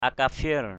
/a-ka-fie̞r/ 1.